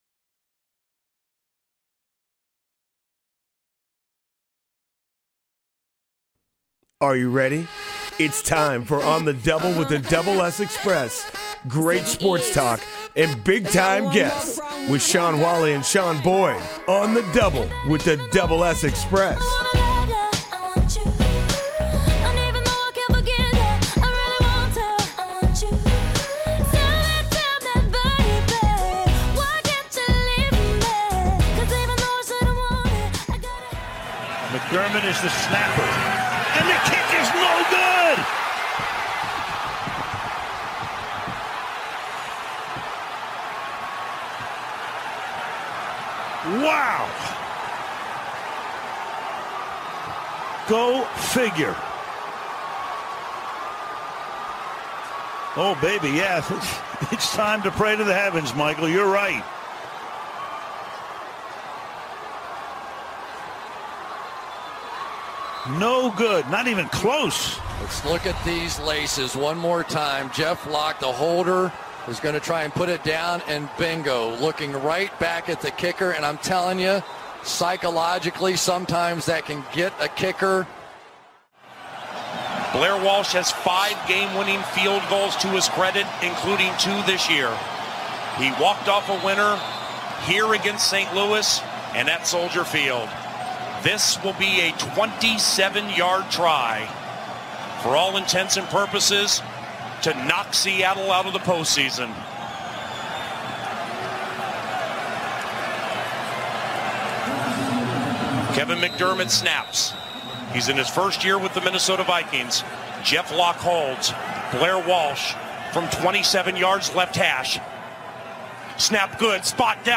Talk Show Episode, Audio Podcast
sports talk show